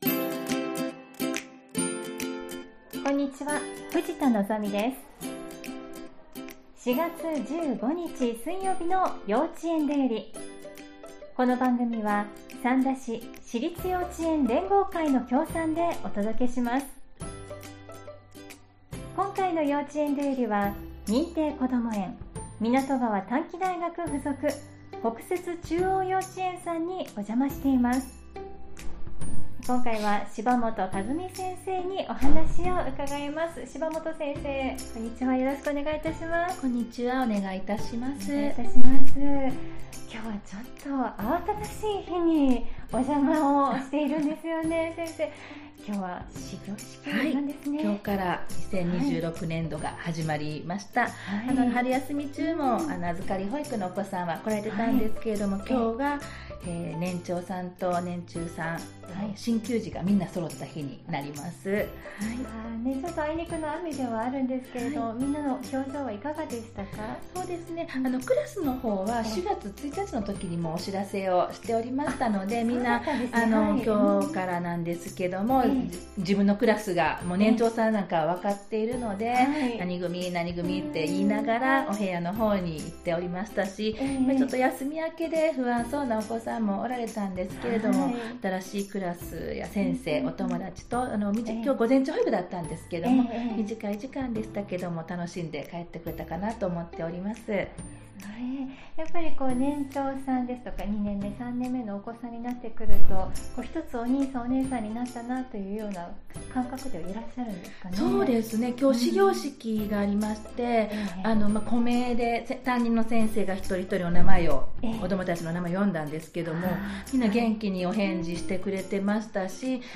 今回の幼稚園だよりは、湊川短期大学附属 北摂中央幼稚園 さんにおじゃましました♪